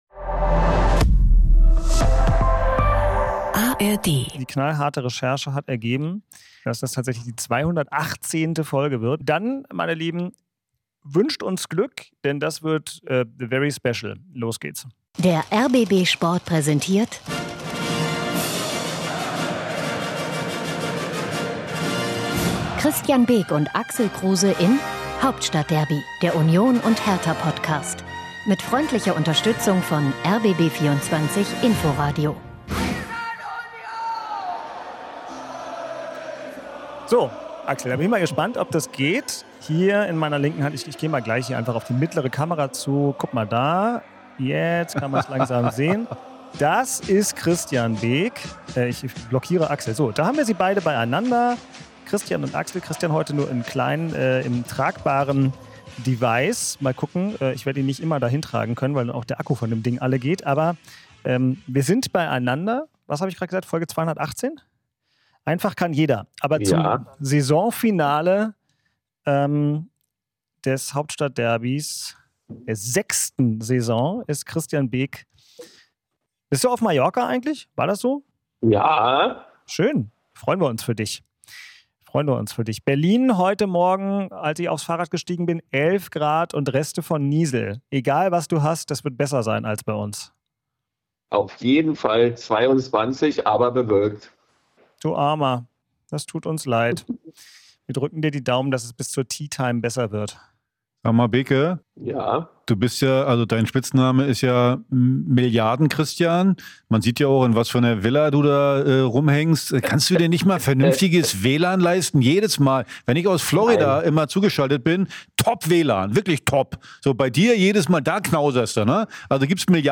Zum Abschluss der sechsten Hauptstadtderby-Saison ist Christian Beeck technisch wacklig aber gut gelaunt aus Mallorca zugeschaltet. Der Ur-Unioner freut sich über einen glücklichen Auswärtssieg seiner Eisernen in Augsburg (04:30).